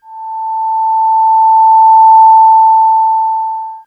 BadTransmission7.wav